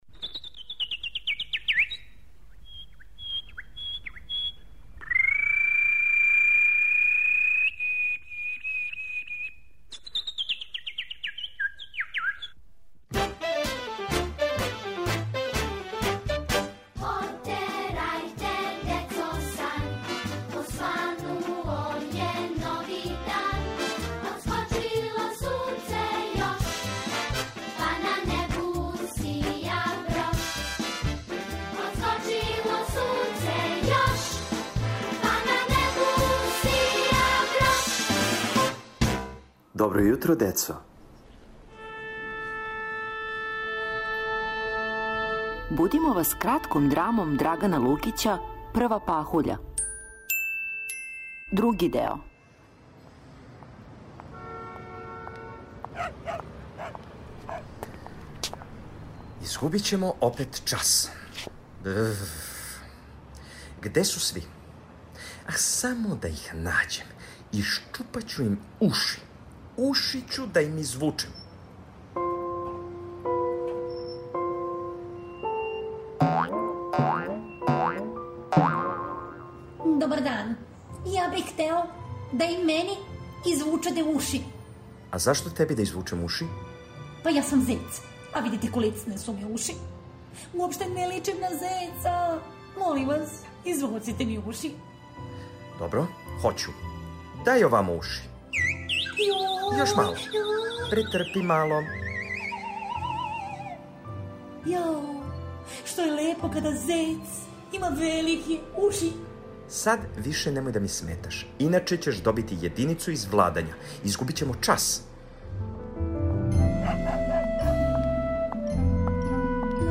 Сазнајте да ли ће деца успети да ухвате пахуљу пре него што се истопи - у драми за децу Драгана Лукића "Прва пахуља".